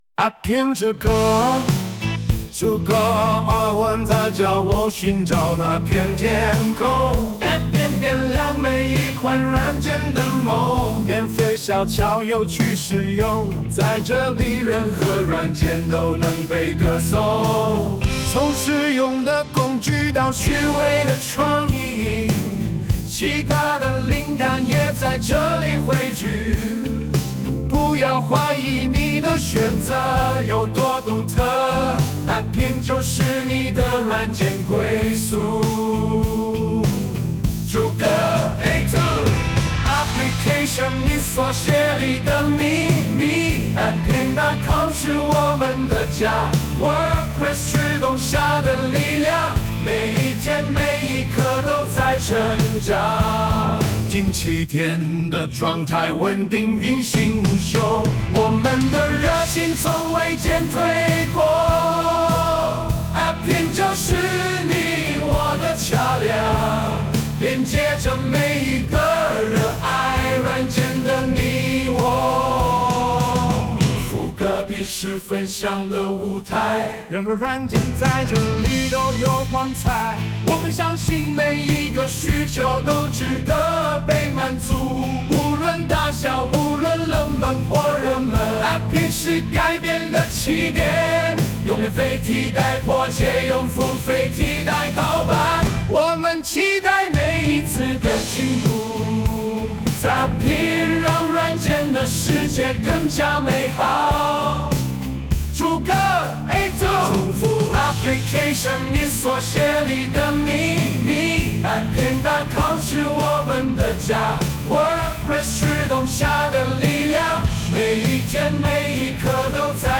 哎呀都是中文呀:joy: 瞧我这听力